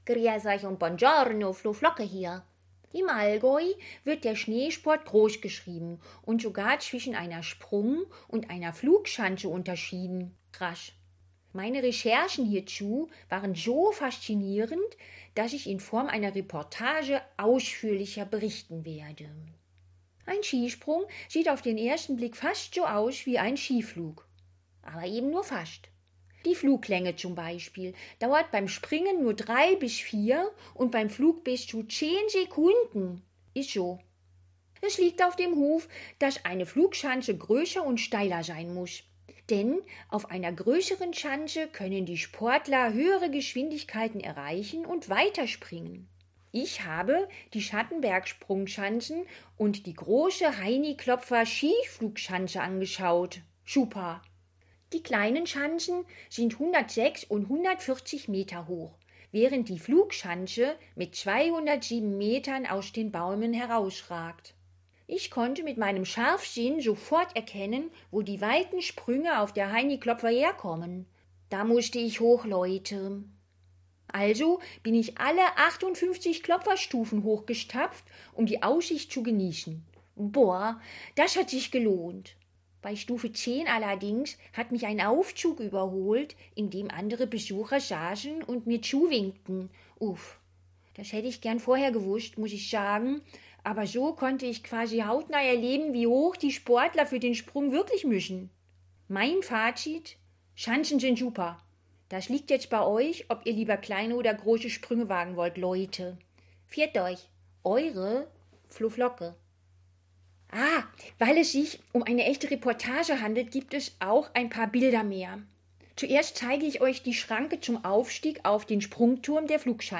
Reportage
Flo.Flocke-Reportage-Schanzen.mp3